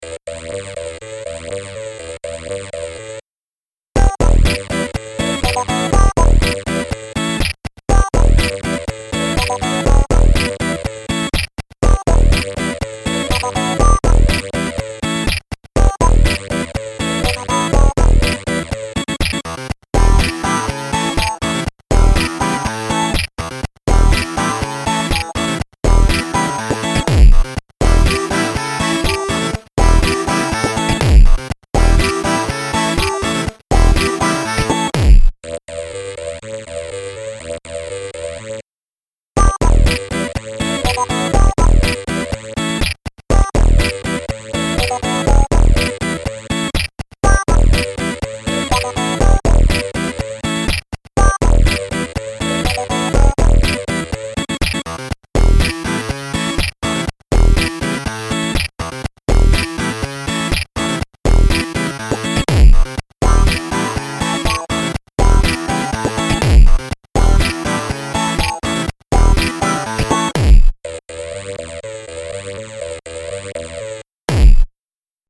Quirky staccato 8-bit electronics with computer game feel.